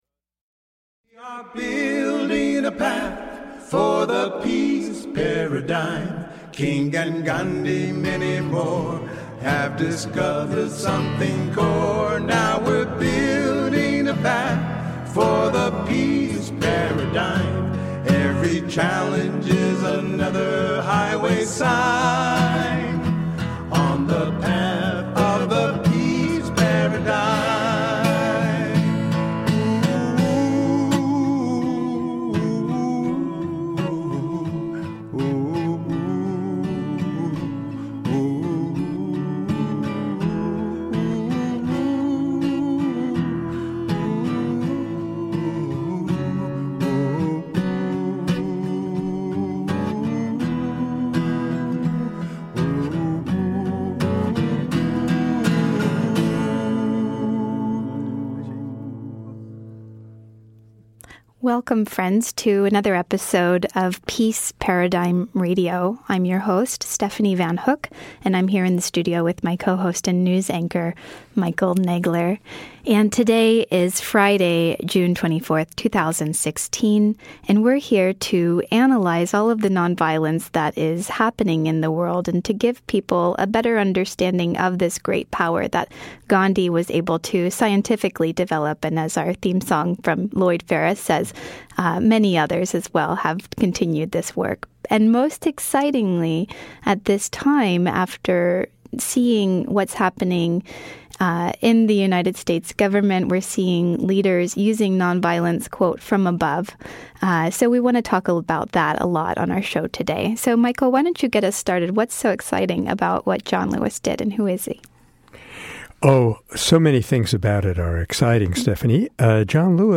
How can nonviolence be used as a response to gun violence? Learn about John Lewis and hear his powerful speech from the house floor. The hosts also analyze the mainstream media’s coverage of this extraordinary story of “nonviolence from above.”